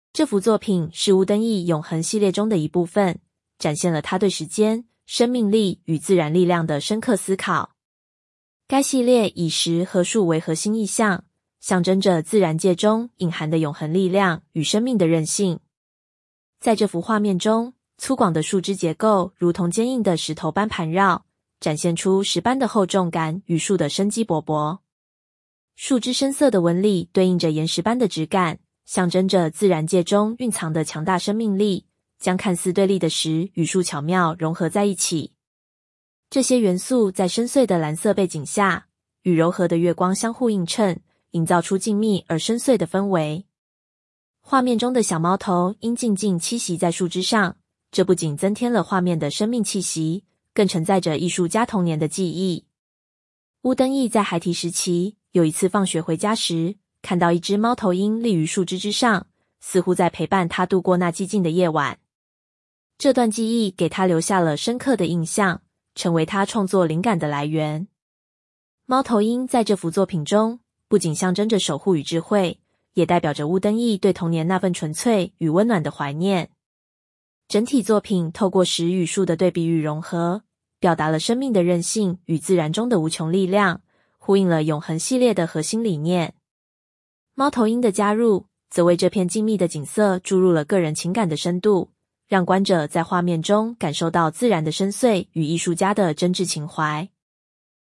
中文語音導覽